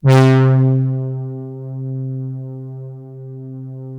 Index of /90_sSampleCDs/Best Service ProSamples vol.55 - Retro Sampler [AKAI] 1CD/Partition D/BRASS PAD